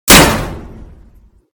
/ gamedata / sounds / material / bullet / collide / metal03.ogg 19 KiB (Stored with Git LFS) Raw History
metal03.ogg